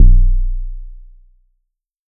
RAGBAG BASS.wav